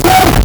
Player_Glitch [24].wav